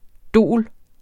Udtale [ ˈdoˀl ]